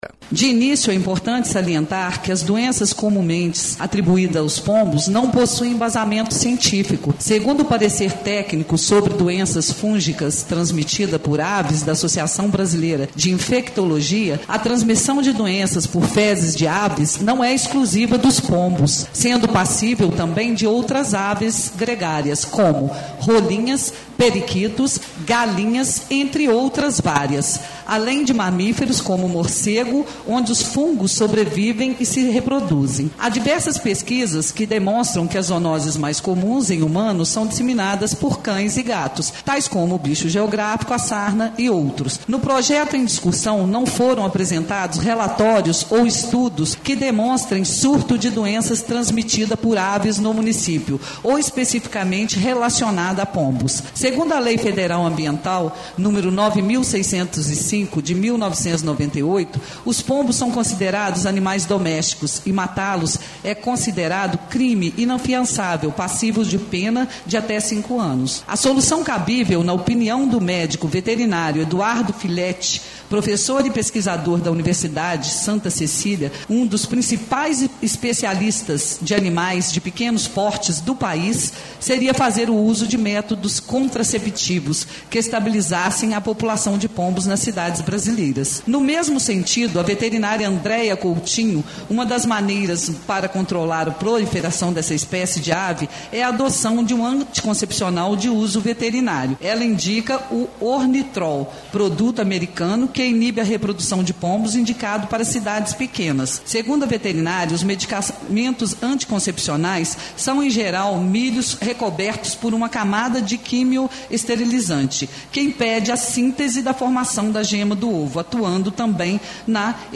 A vereador Jane Lacerda – na sessão ordinária do dia 11 de novembro – apresentou uma emenda a PL – a vereadora já havia pedido vistas sobre o Projeto. Em sua emenda foi sugerido alterar parte do texto e métodos contraceptivos para manter o controle populacional.
Vereadora Jane Lacerda/PHS